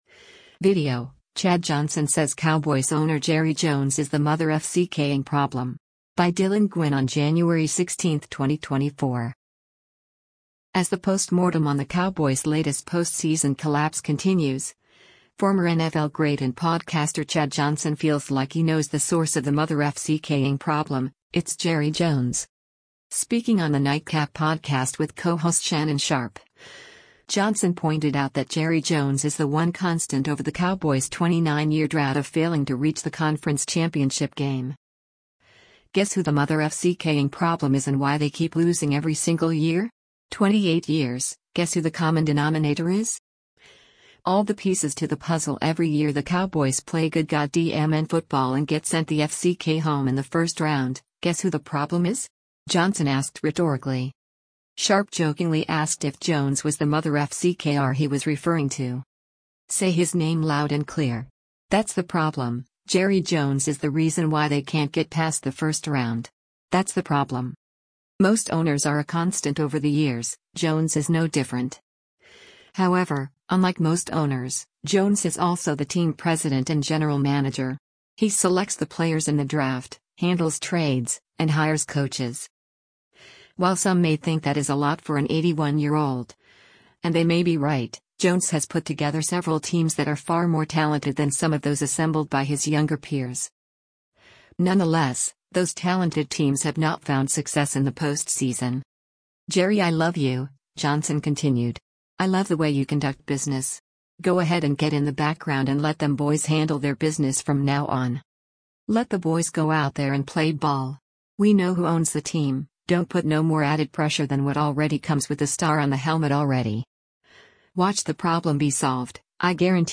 Speaking on the Nightcap Podcast with co-host Shannon Sharpe, Johnson pointed out that Jerry Jones is the one constant over the Cowboys 29-year drought of failing to reach the conference championship game.
Sharpe jokingly asked if Jones was the “motherf*ck*r” he was referring to.